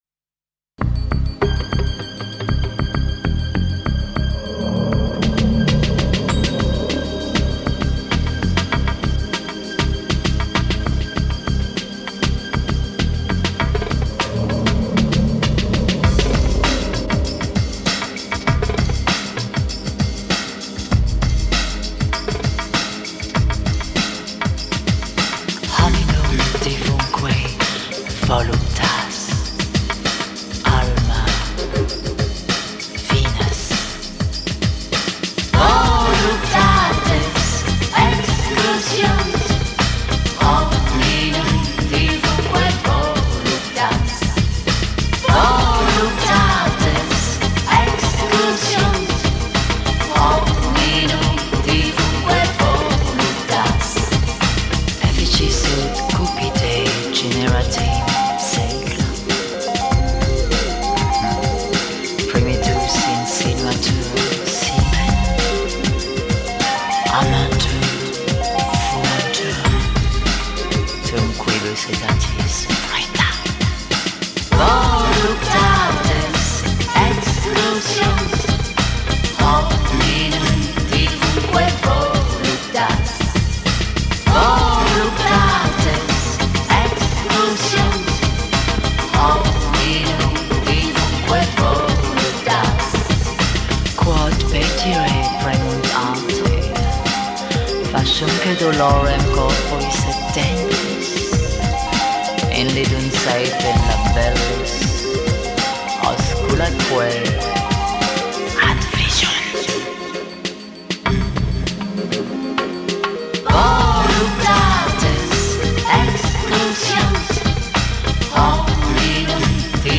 танцевальный, в стиле латино, трек